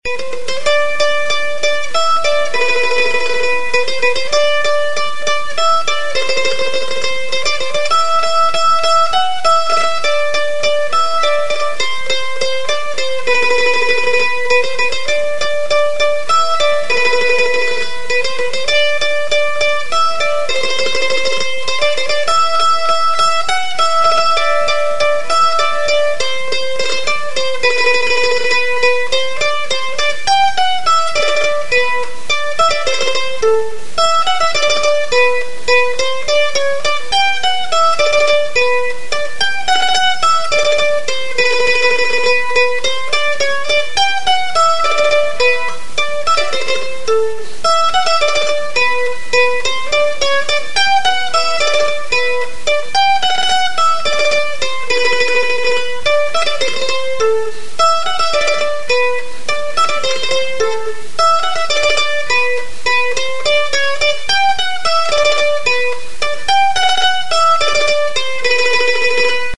Bandurria joaldia. Lasarteko Errondaila.
Cordes -> Pincées
Sei soka bikoitz dituen kordofonoa da.